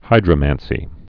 (hīdrə-mănsē)